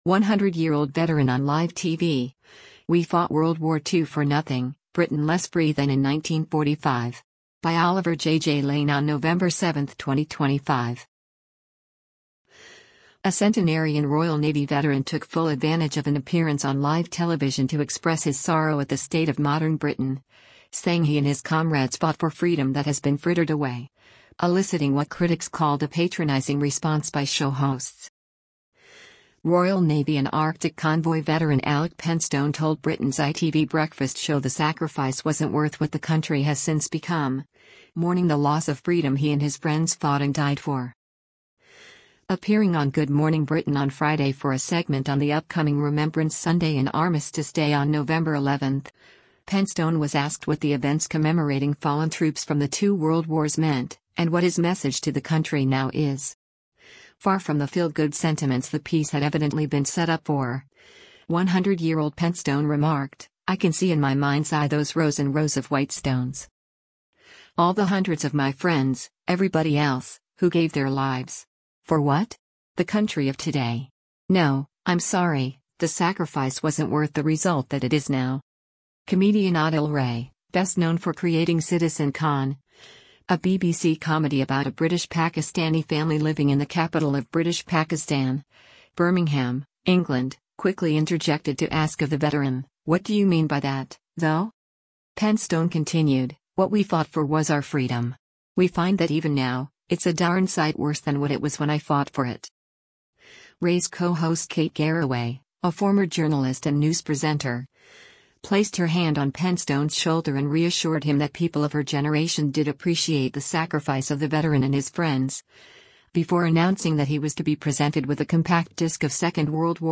100-Year-Old Veteran on Live TV: We Fought WW2 for Nothing